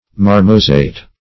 marmozet - definition of marmozet - synonyms, pronunciation, spelling from Free Dictionary Search Result for " marmozet" : The Collaborative International Dictionary of English v.0.48: Marmozet \Mar"mo*zet`\, n. See Marmoset .
marmozet.mp3